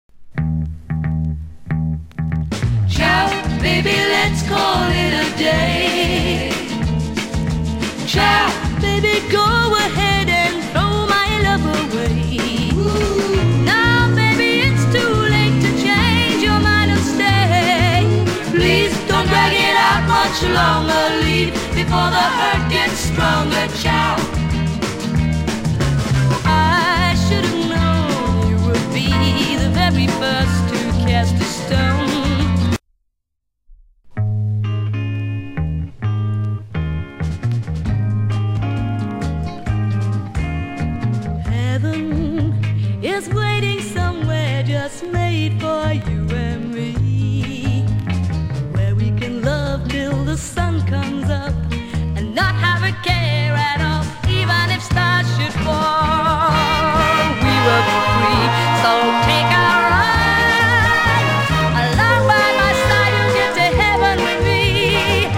イントロのベースから躍動感あふれるアレンジに心が踊る。
(税込￥4950)   FEMALE / SOFT ROCK